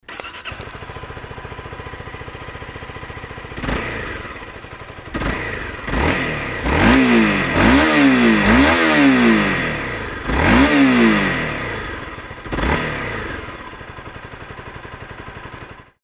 ↑前期型のマフラー・・
排気音のサンプル
サンプルの排気音は全く同じ条件で
cbr250r_zenki.mp3